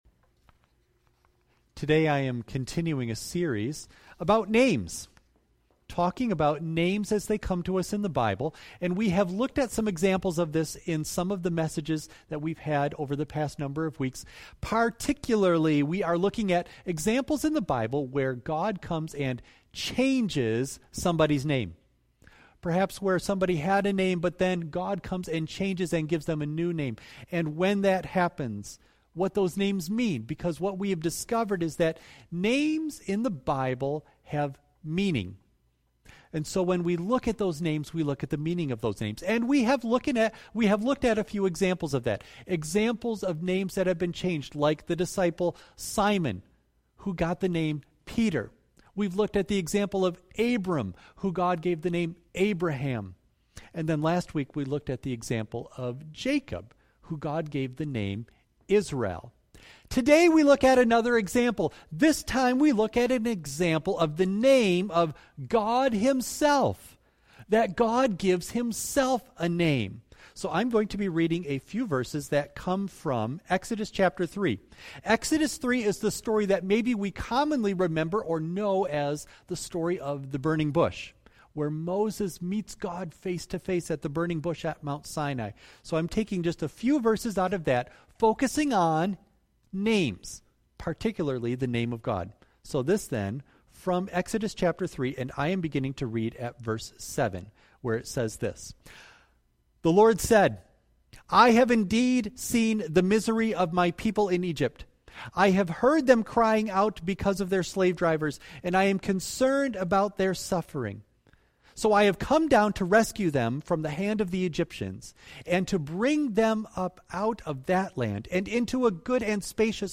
Audio only of message